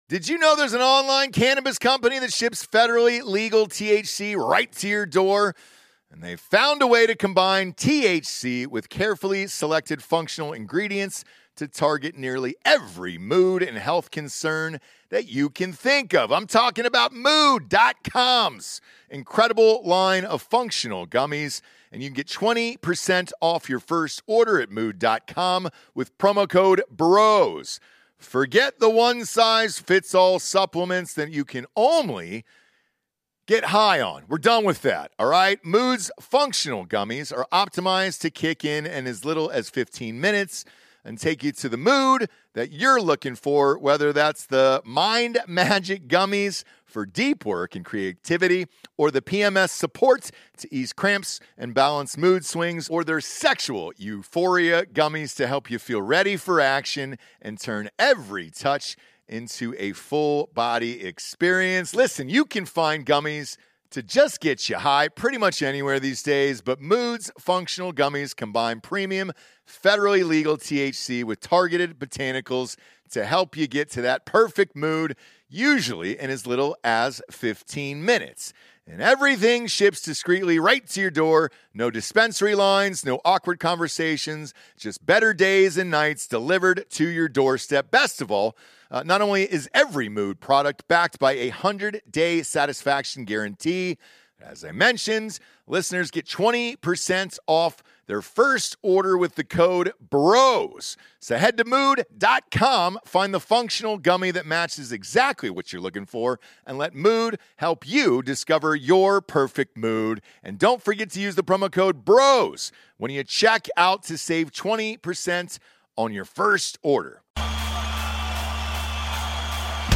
Episode 837 - LIVE from Miami